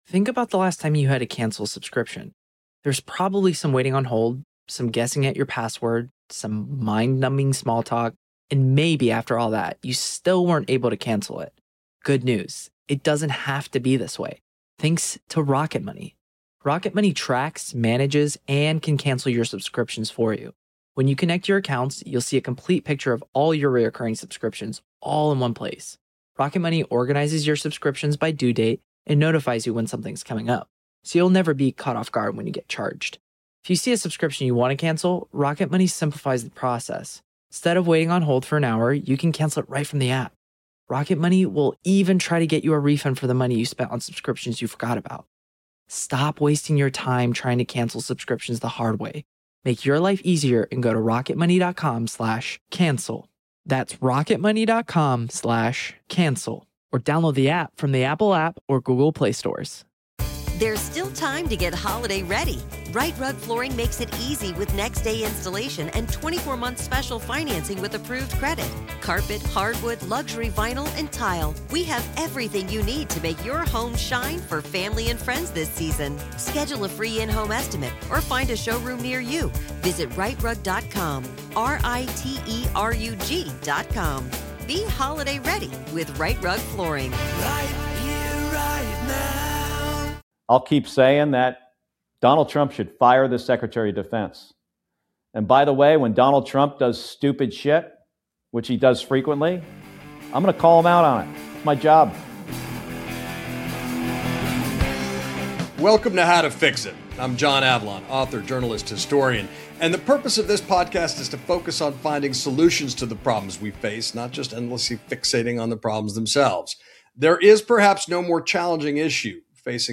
Senator Mark Kelly sits down with John Avlon to discuss Pentagon intimidation, the rule of law, and his AI for America plan, covering job displacement, data-center energy demands, and what it will take for the U.S. to beat China on AI without chaos.